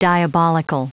Prononciation du mot diabolical en anglais (fichier audio)
Prononciation du mot : diabolical